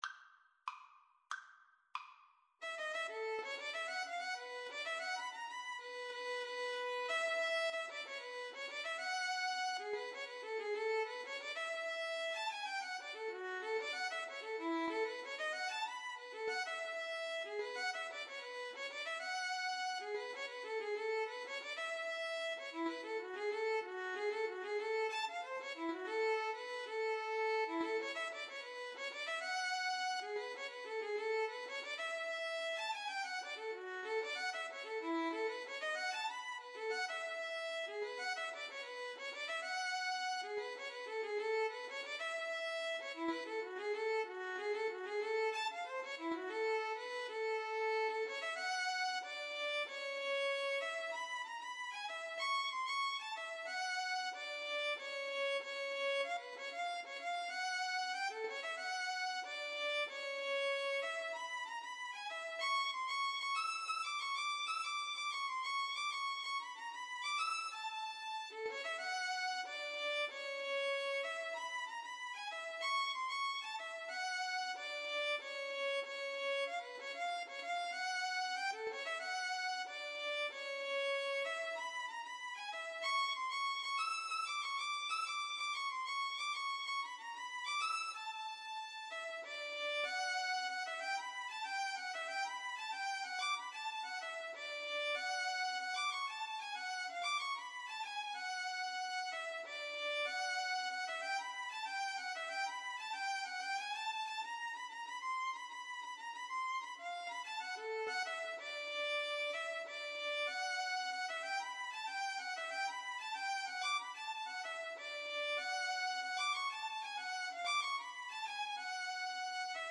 Free Sheet music for Violin-Guitar Duet
A major (Sounding Pitch) (View more A major Music for Violin-Guitar Duet )
Slow march tempo. = 94 Slow march tempo
2/4 (View more 2/4 Music)
Jazz (View more Jazz Violin-Guitar Duet Music)